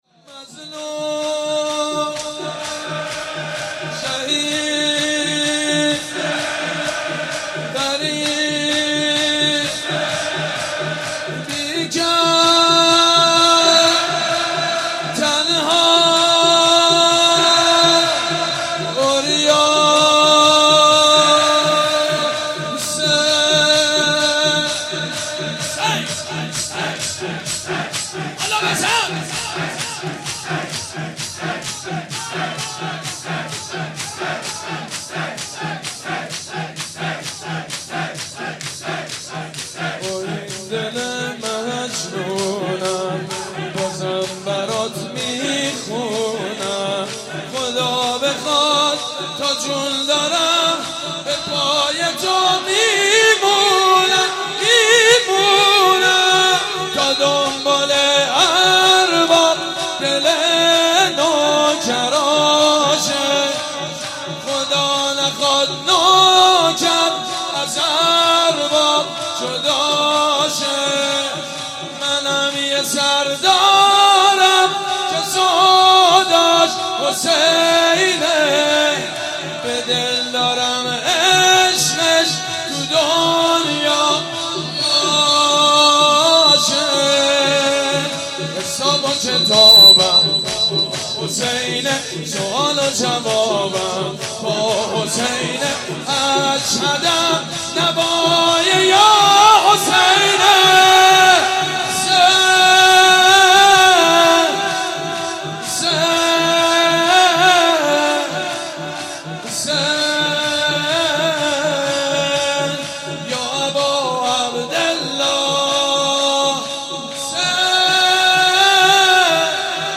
«شهادت امام جواد 1393» شور: مظلوم حسین